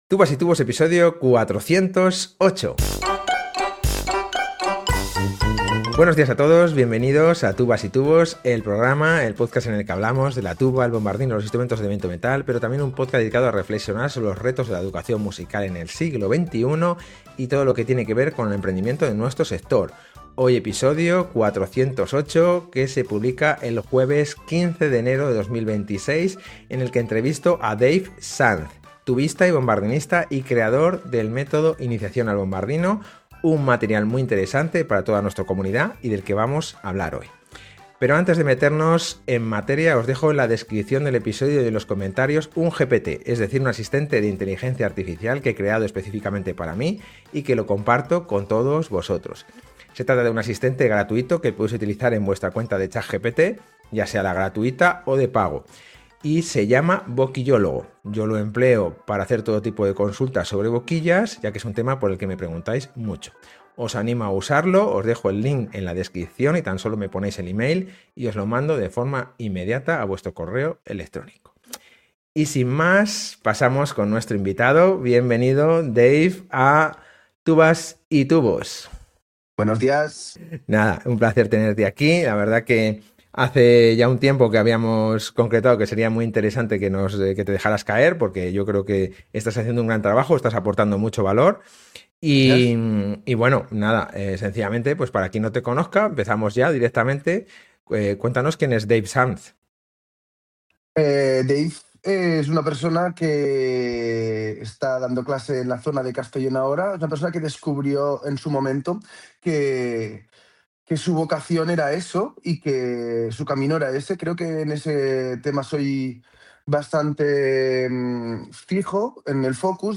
Interesante entrevista